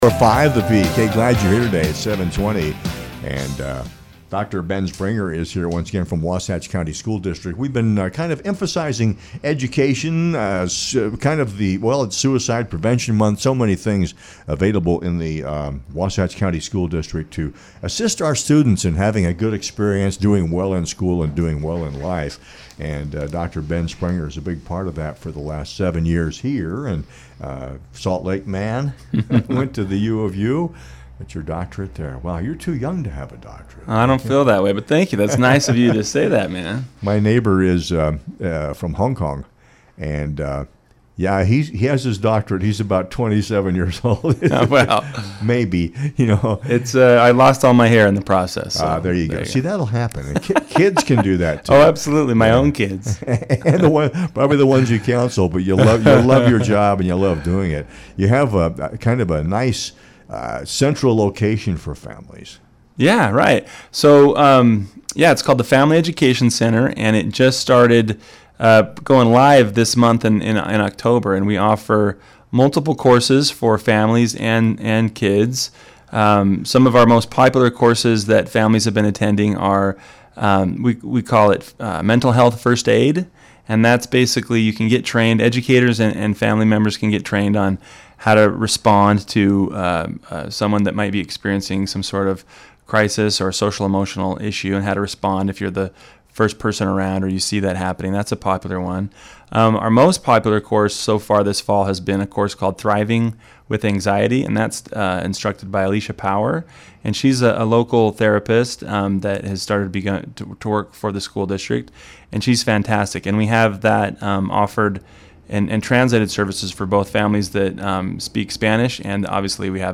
The mission of Wasatch Wellness is to provide a physical location with reliable resources for kids, faculty, staff, and community members beyond the school day. Listen to their interview from 94.5 The Peak Voices of the Valley radio show: